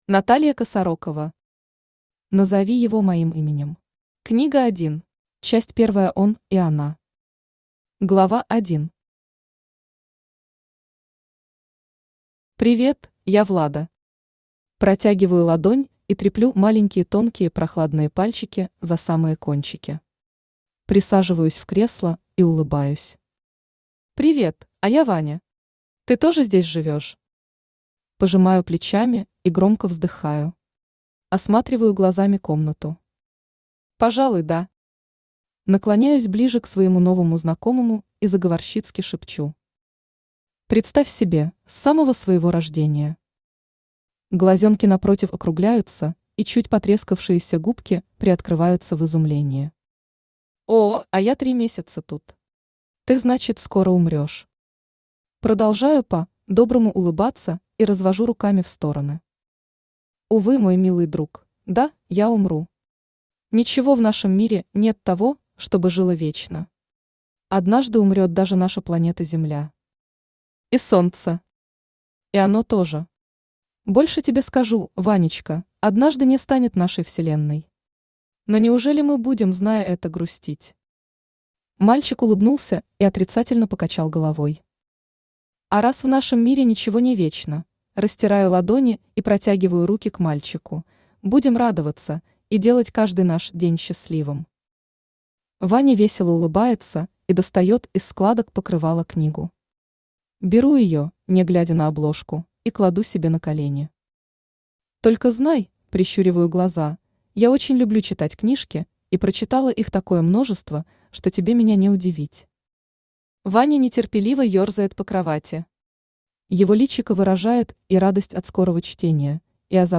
Аудиокнига Назови его моим именем. Книга 1 | Библиотека аудиокниг
Книга 1 Автор Наталья Владимировна Косарокова Читает аудиокнигу Авточтец ЛитРес.